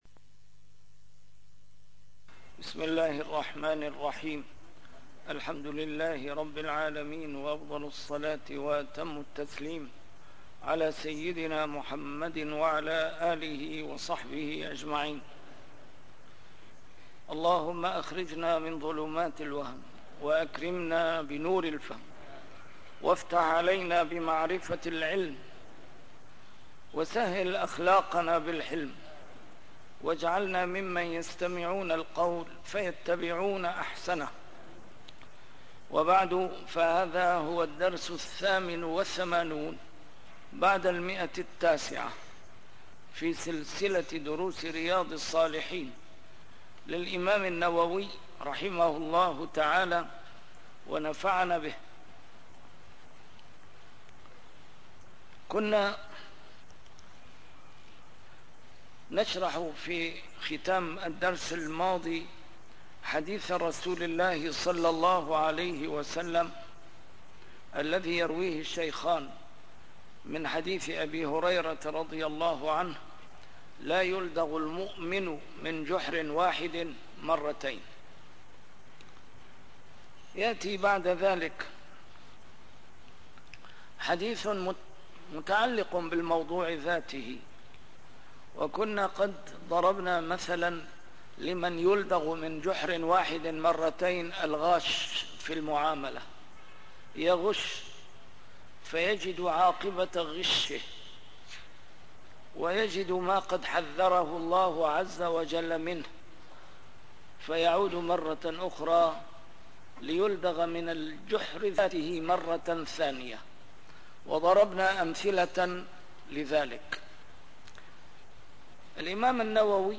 A MARTYR SCHOLAR: IMAM MUHAMMAD SAEED RAMADAN AL-BOUTI - الدروس العلمية - شرح كتاب رياض الصالحين - 988- شرح رياض الصالحين: بابُ المنثورات والمُلَح